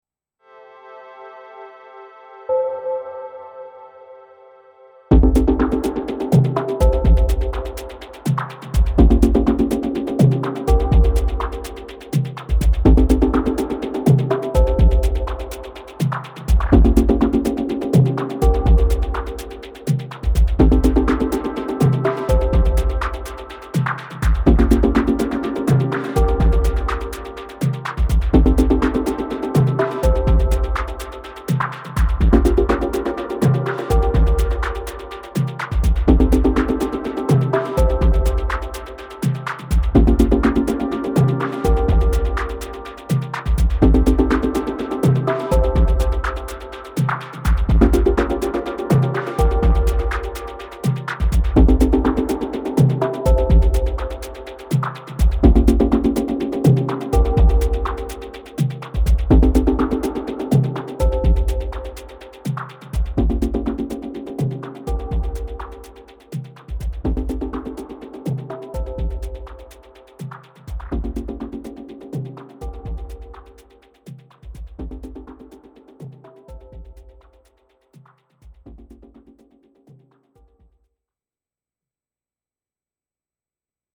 I went for quantity over quality this time :sweat_smile: This is all over the place, but quite firmly in the melodic/tonal camp.
Murky beats and textures.